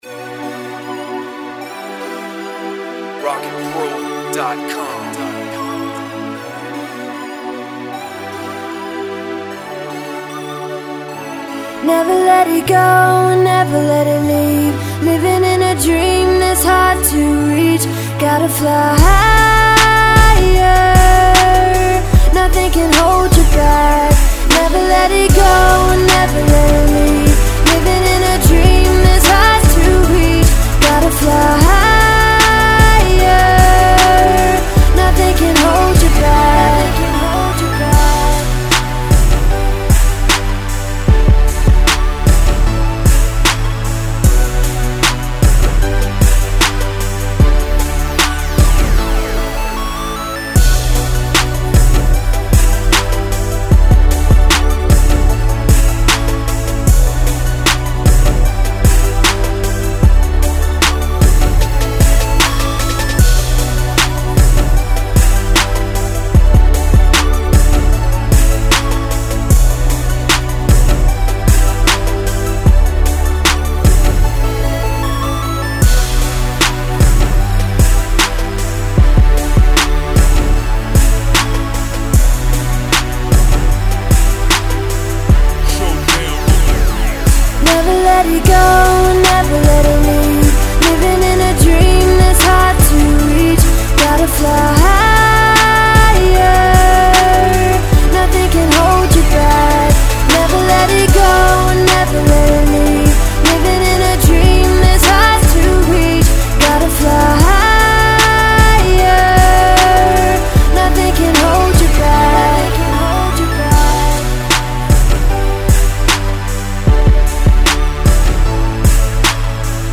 76 BPM.
with hook
Beats With Hooks
R&B